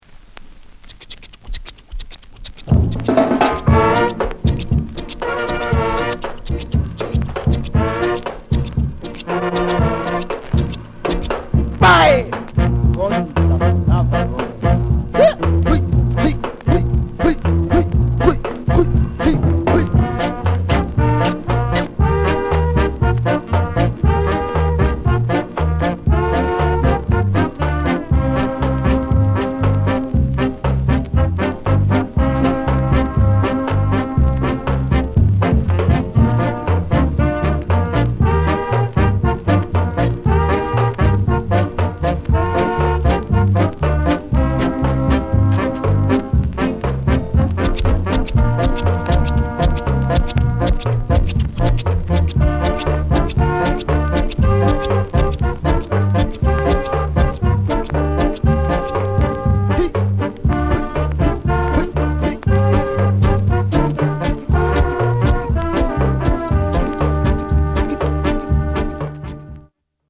AUTHENTIC SKA